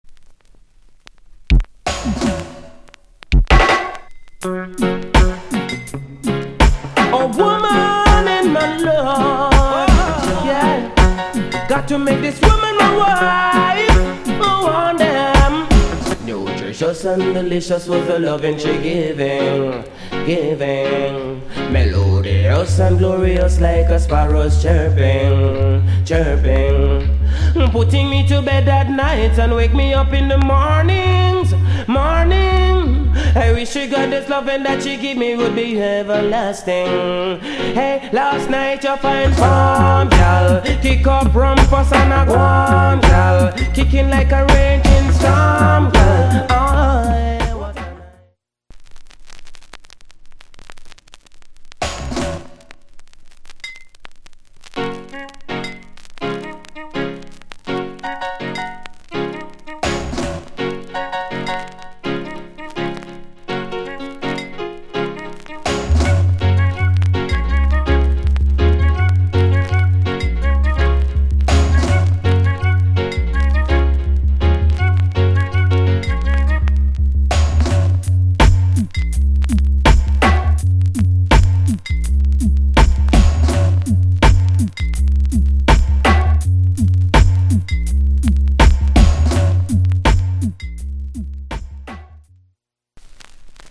Male Vocal Condition VG+ Soundclip